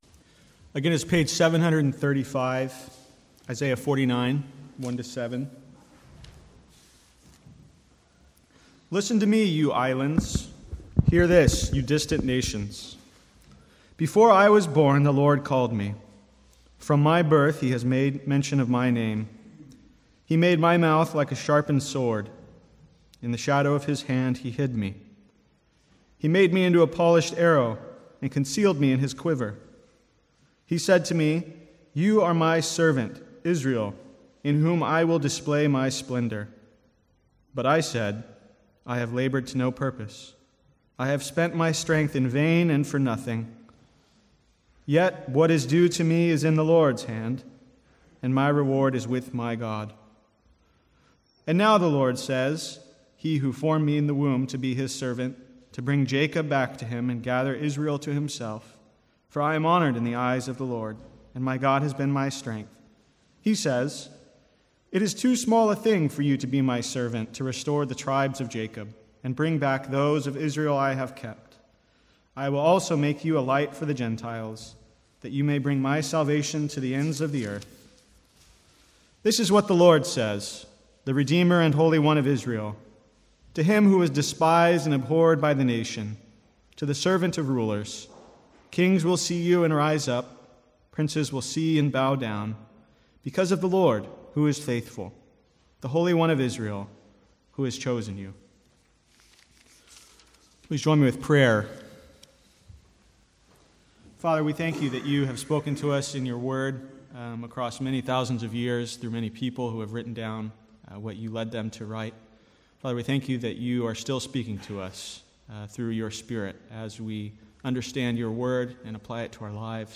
Sermons | St Andrews Free Church
From the Sunday morning series in the Servant Songs of Isaiah.